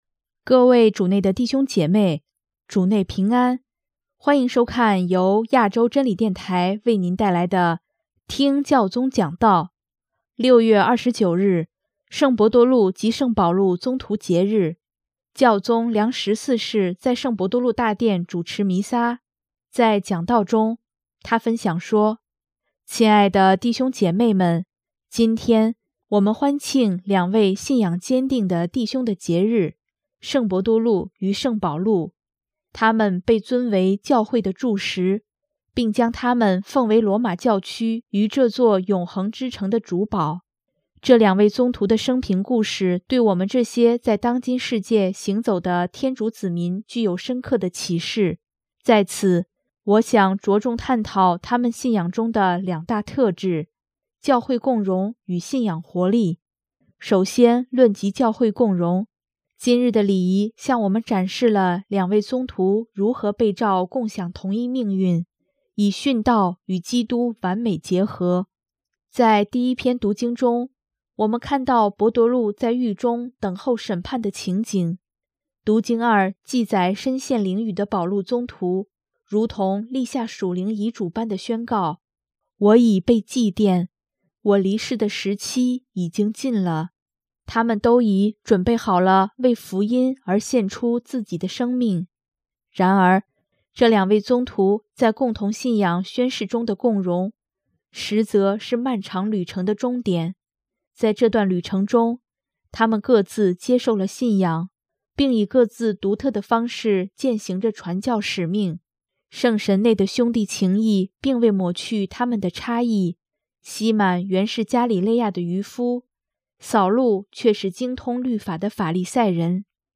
6月29日，圣伯多禄及圣保禄宗徒节日，教宗良十四世在圣伯多禄大殿主持弥撒，在讲道中，他分享说：